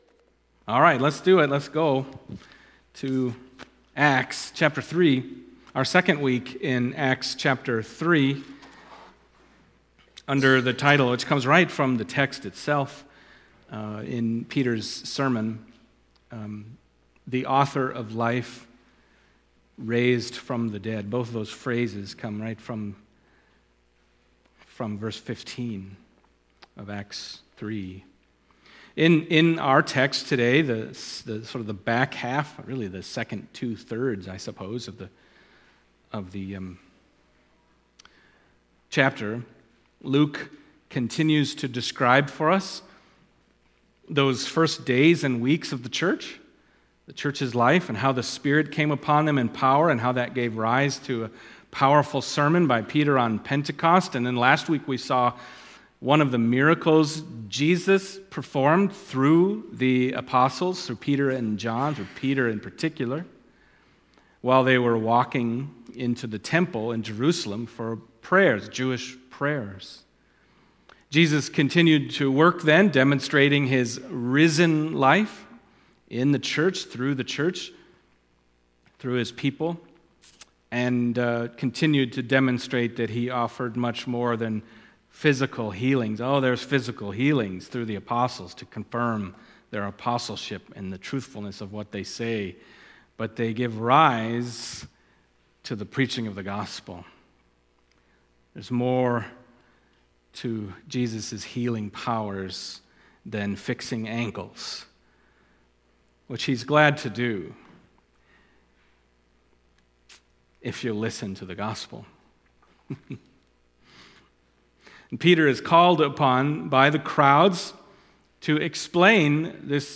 Acts Passage: Acts 3:1-26 Service Type: Sunday Morning Acts 3:1-26